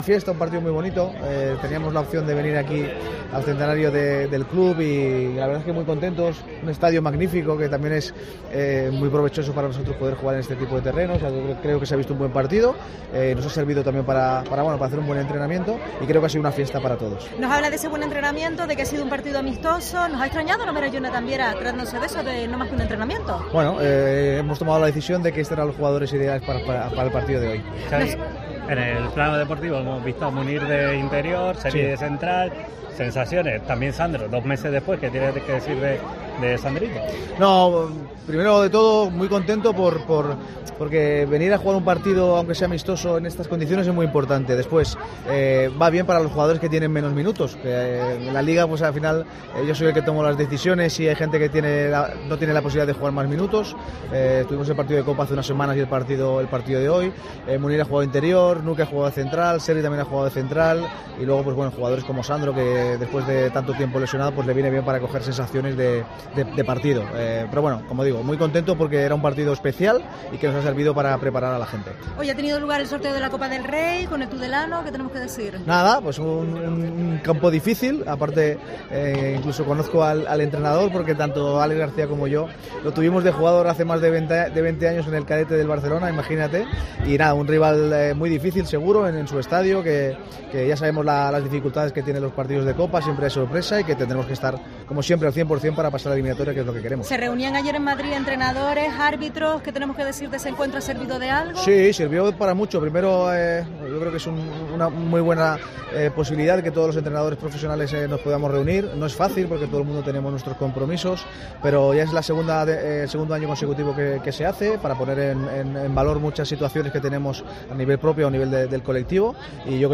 Tras el enfretamiento hablaba el técnico de la UD, valorando inicialmente la importancia de la visita de la entidad amarilla a la SD Tenisca en una fecha tan señalada: "Ha sido un partido muy bonito. Teníamos la opción de venir aquí a la celebración del Centenario del club y la verdad es que nos vamos muy contentos. Un estadio magnífico que también es importante para jugar este tipo de partidos". Y añadió: "ha sido un buen entrenamiento y creo que una fiesta para todos".